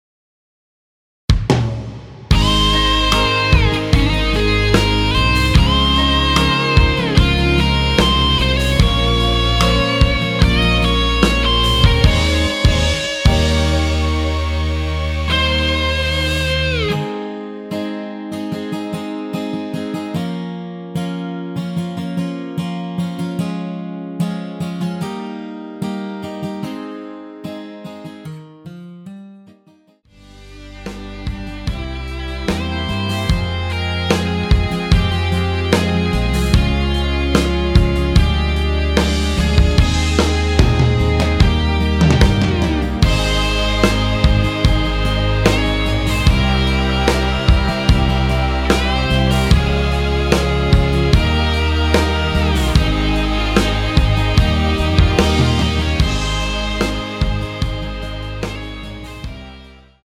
원키에서(+1)올린 MR입니다.
Ab
◈ 곡명 옆 (-1)은 반음 내림, (+1)은 반음 올림 입니다.
앞부분30초, 뒷부분30초씩 편집해서 올려 드리고 있습니다.
중간에 음이 끈어지고 다시 나오는 이유는